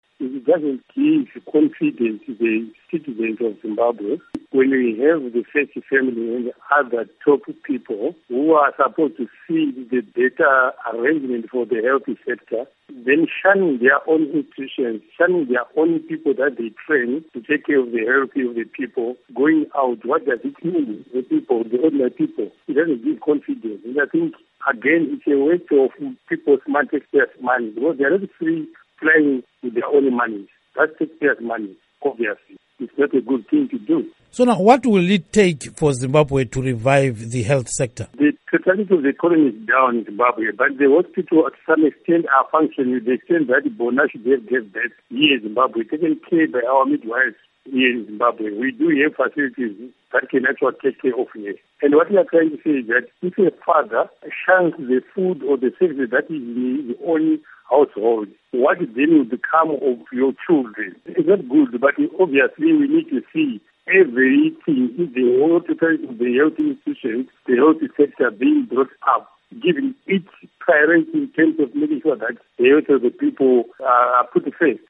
Interview With Blessing Chebundo on Bona Mugabe Chikore Maternity Visit